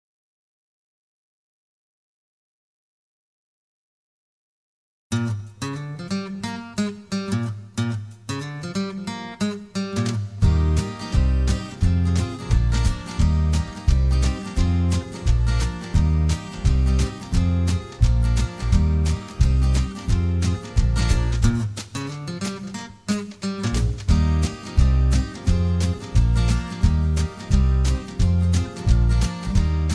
rock and roll, r and b, easy listening, backing tracks